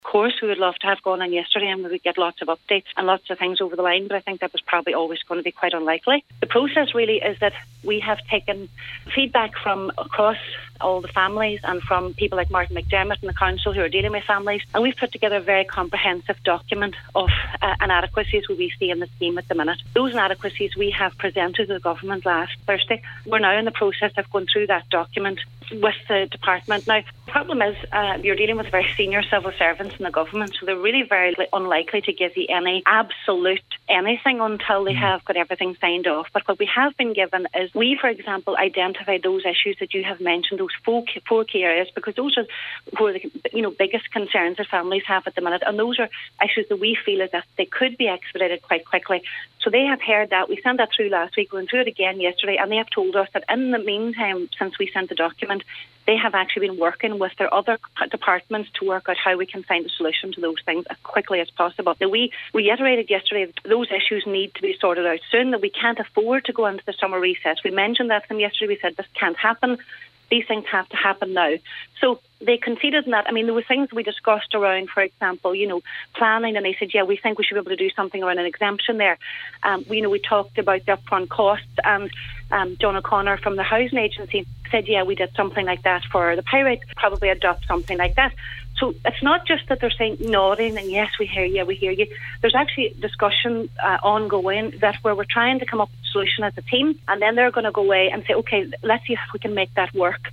told the Nine til Noon Show that, they will not allow discussions be rushed: